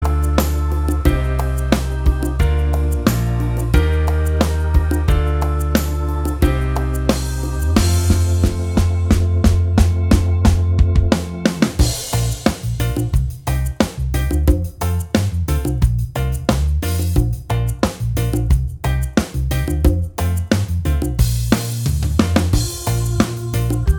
Minus All Guitars Pop (2010s) 4:28 Buy £1.50